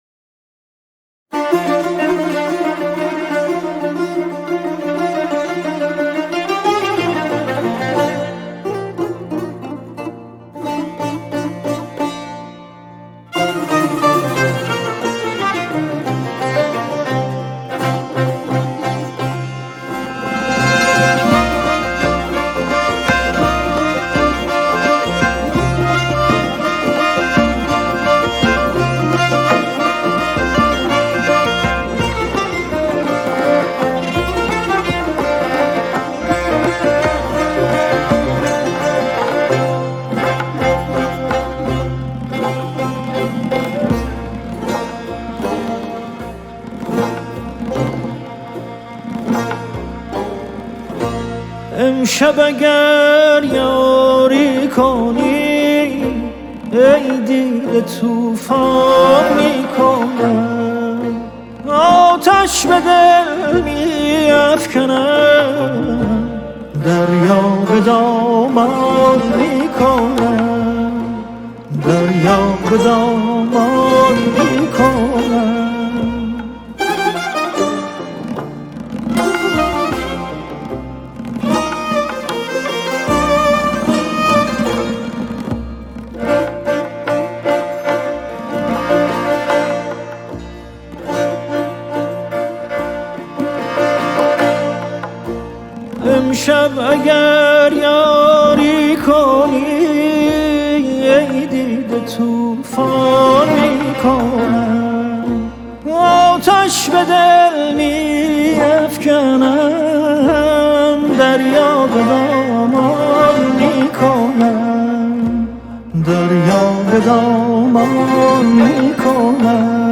آهنگ سنتی